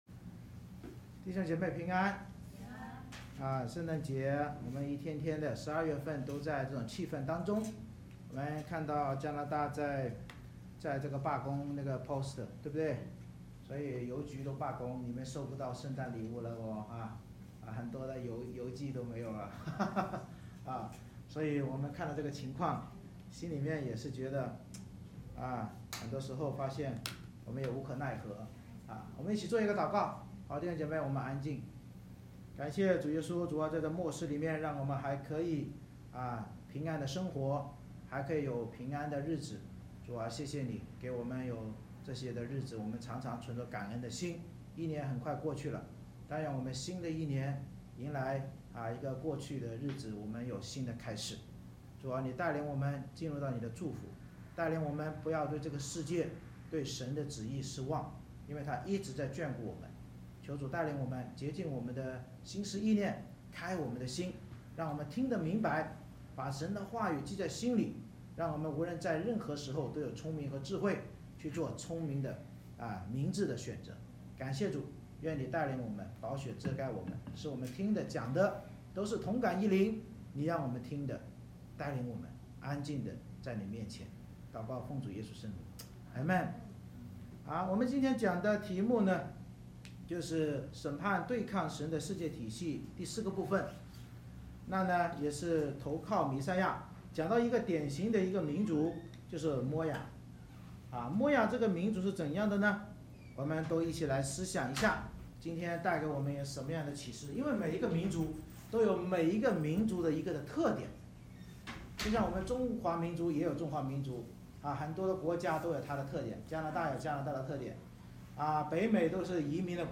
以赛亚书15:1-16:14 Service Type: 主日崇拜 先知领受摩押的默示